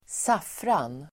Ladda ner uttalet
saffran substantiv, saffron Uttal: [²s'af:ran] Böjningar: saffranen Definition: starkt gulfärgande krydda från liljeväxten Crocus sativus Sammansättningar: saffrans|gul (saffron yellow), saffrans|bulle (saffron bun)